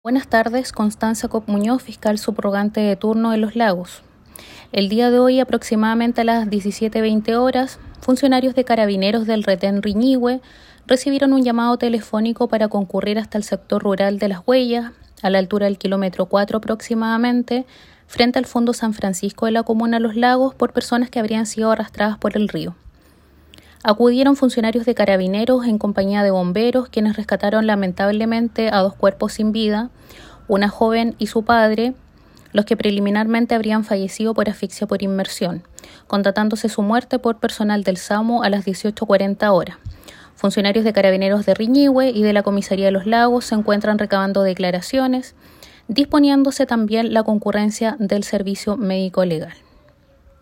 Constanza Kopp la fiscal subrogante de Los Lagos